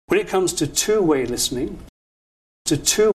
A good way to illustrate this is with real utterances in which native speakers say the words to two consecutively: /tə tuː/ or /t tuː/. Notice the weakness of to and the prominence of two: